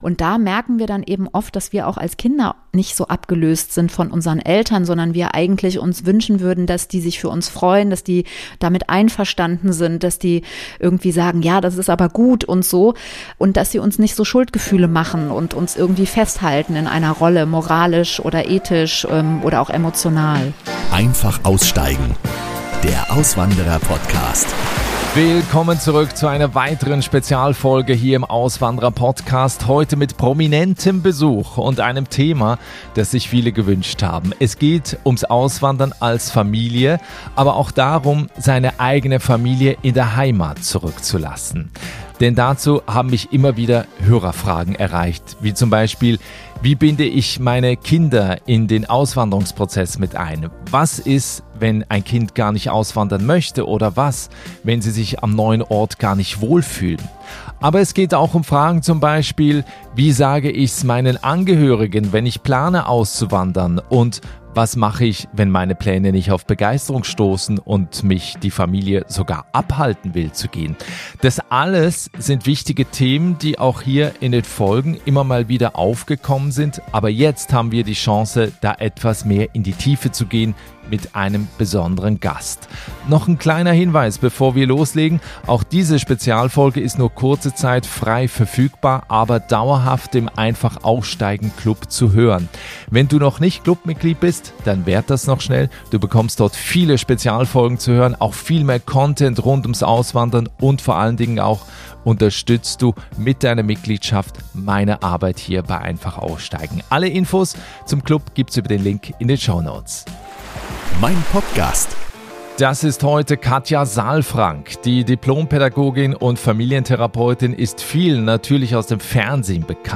Darum geht es heute in dieser Spezialfolge mit Familientherapeutin, Dipl. Pädagogin & Bestseller-Autorin Katia Saalfrank. Wir gehen den Fragen nach, die viele von euch gestellt haben: Wie bindest du Kinder in den Auswanderungsprozess ein? Was tun, wenn sie sich im neuen Land nicht wohlfühlen?